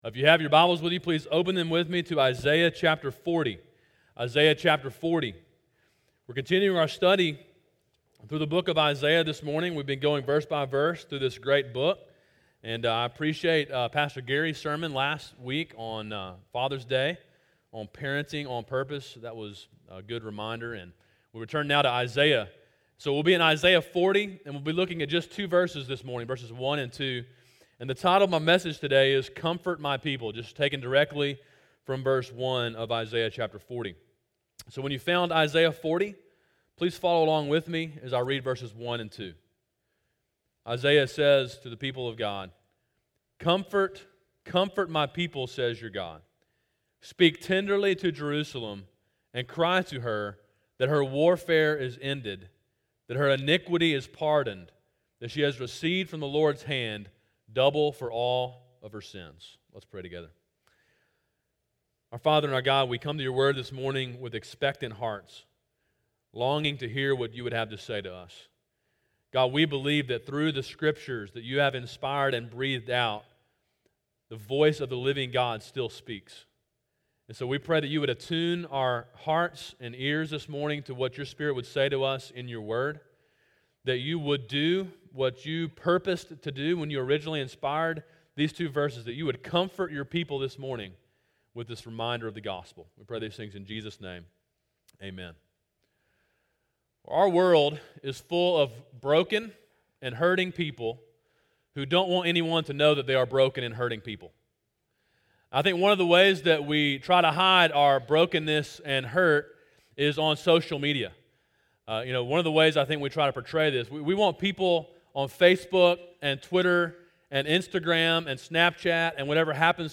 Sermon: “Comfort My People” (Isaiah 40:1-2)
Sermon in a series on the book of Isaiah.